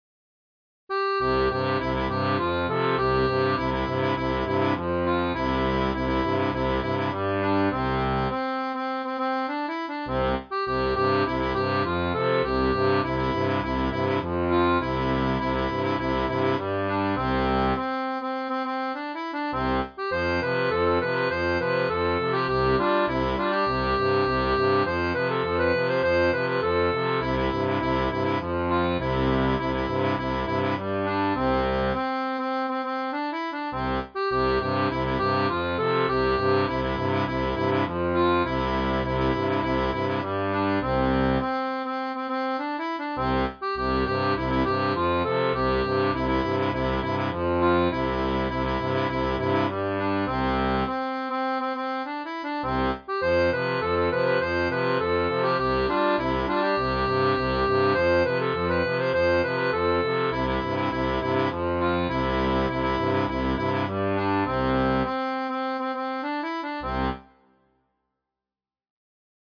• Une tablature pour diato 2 rangs transposée en DO
Type d'accordéon
Pop-Rock